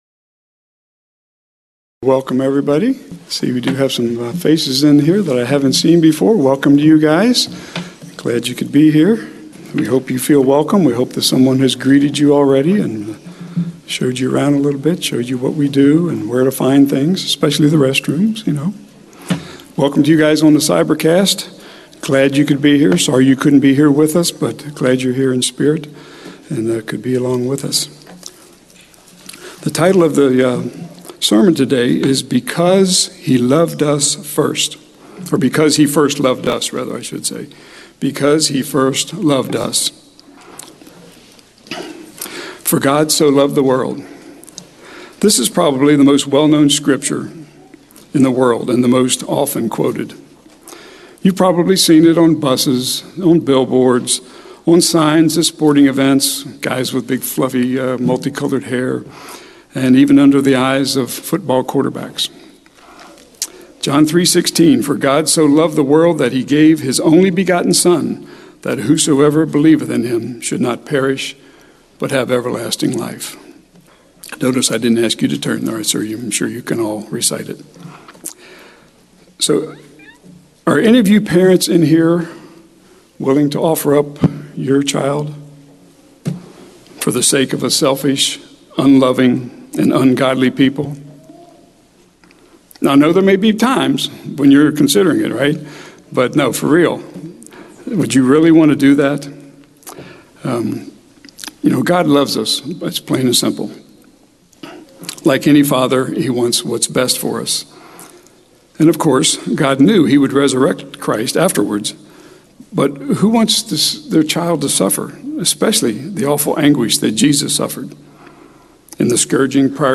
Given in Houston, TX
split sermon